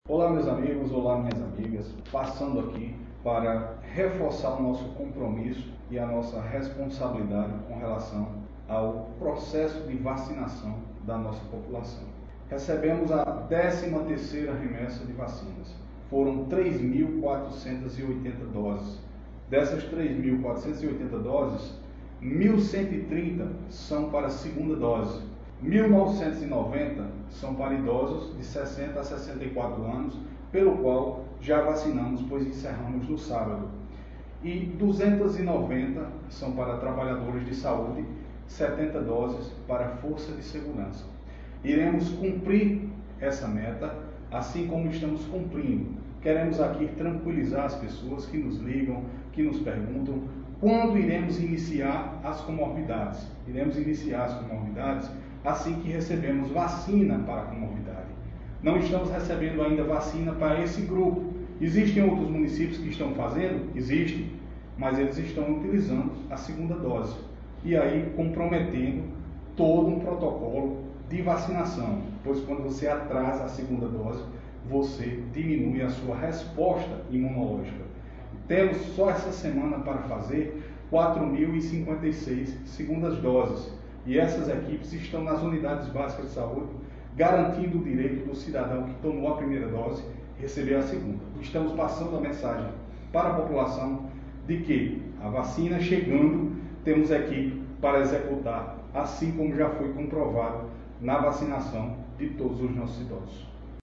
SEGUNDO BRITO – SEC. DE SAÚDE (REMESSA 13 DE VACINAS COVID-19) https